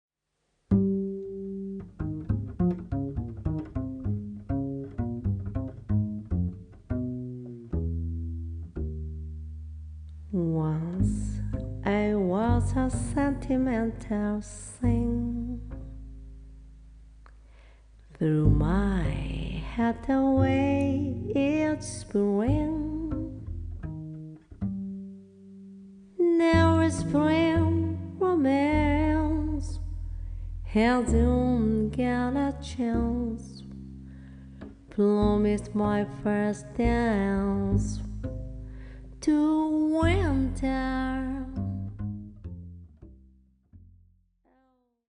Recorded on Dec. 22nd and 23rd, 2024 at Studio Dede, Tokyo